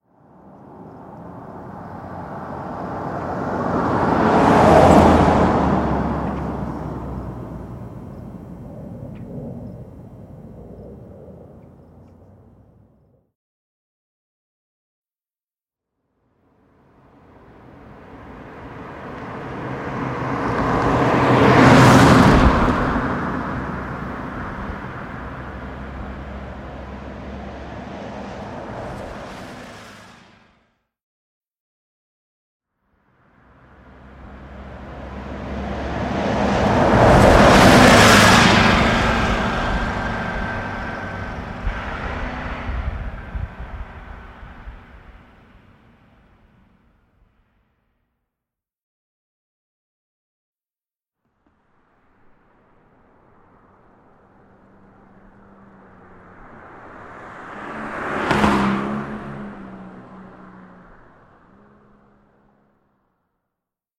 随机的" 汽车卡车重型货车汽车通过中速真正的嘎吱嘎吱的好远的高速公路的角度也许
描述：汽车卡车重型货车汽车通过中等速度真正脆脆好遥远的远高速公路透视maybe.flac